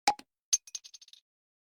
bottle.mp3